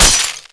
rifle_hit_glass2.wav